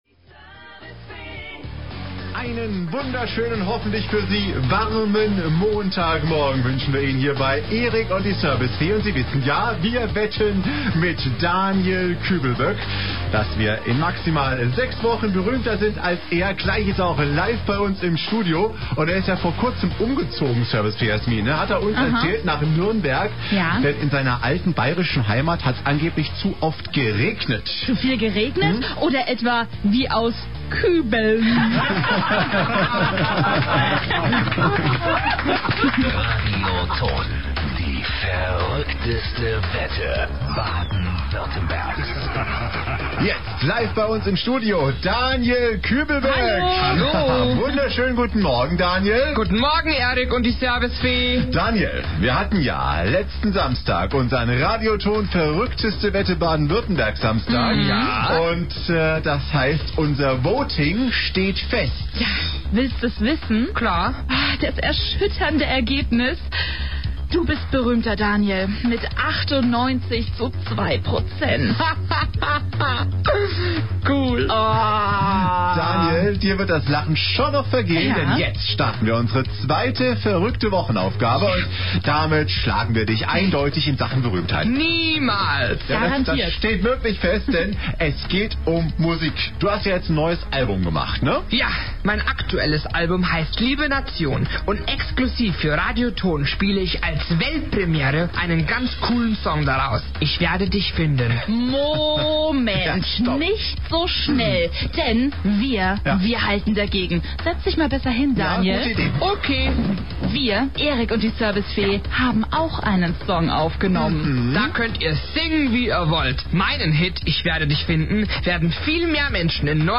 Telefon-Interview bei "Radio Ton"